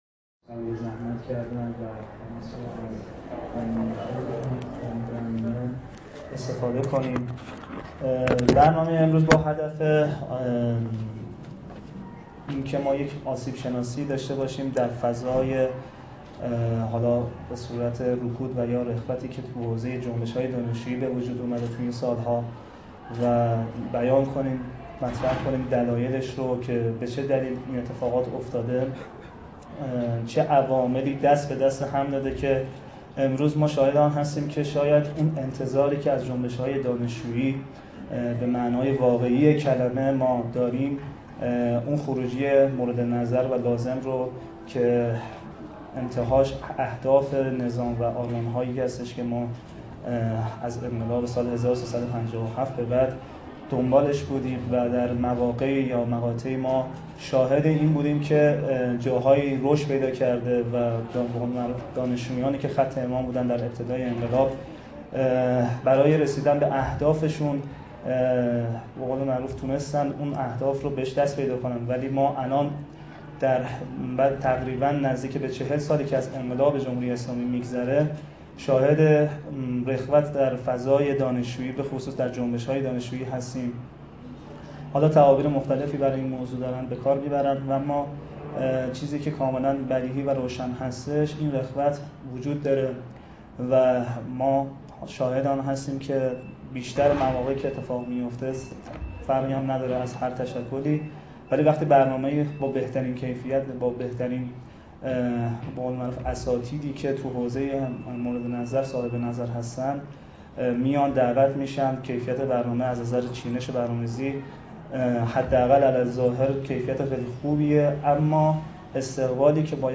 کرسی آزاد اندیشی «صدای گوش خراش سکوت!» با حضور نمایندگان تشکل‌های دانشجویی در سالن شهید مطهری دانشکده علوم اجتماعی دانشگاه علامه طباطبائی برگزار شد.